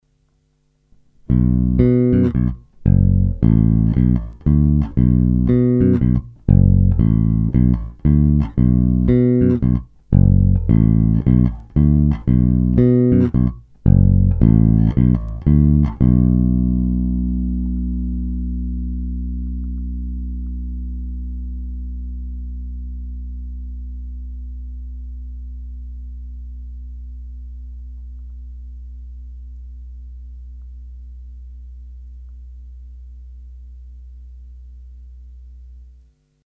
Naprosto typický Jazz Bass, ve zvuku hodně vnímám rezonanci samotného nástroje a desítky let jeho vyhrávání.
Není-li uvedeno jinak, následující nahrávky jsou vyvedeny rovnou do zvukovky, s plně otevřenou tónovou clonou a jen normalizovány, jinak ponechány bez úprav.
Oba snímače